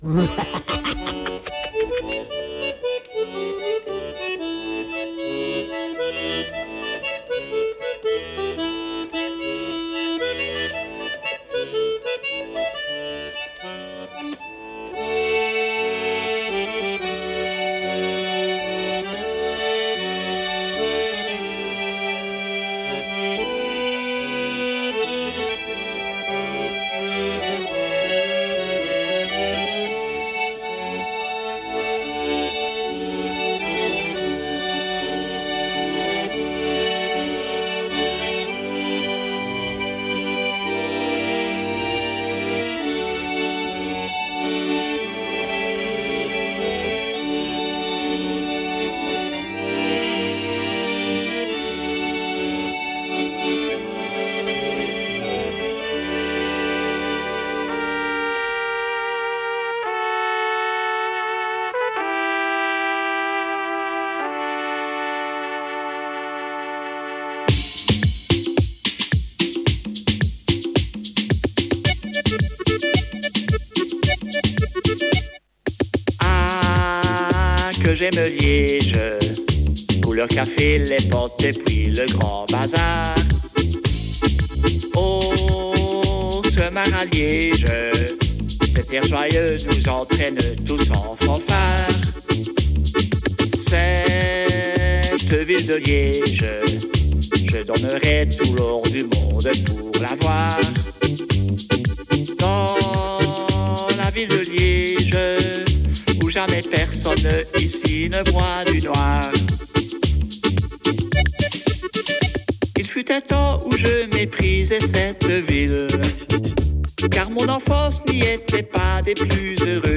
musiques expérimentales et expérimentations sonores